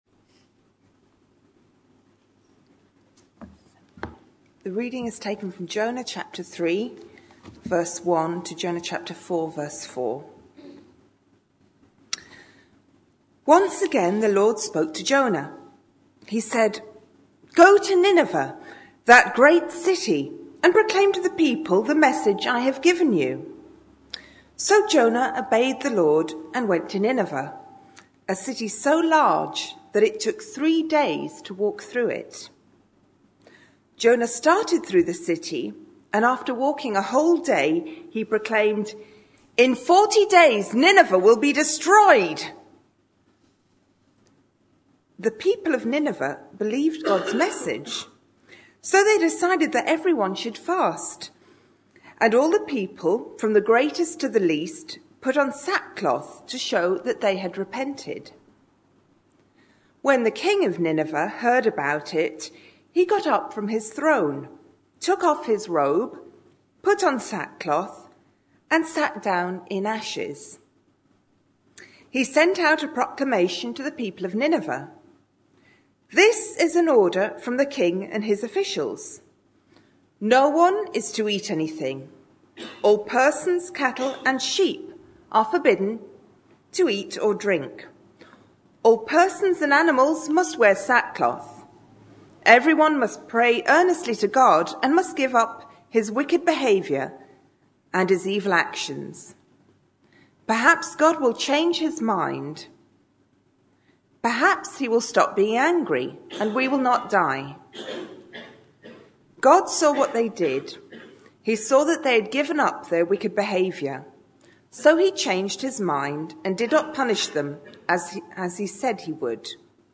A sermon preached on 8th June, 2014, as part of our Connecting With Culture series.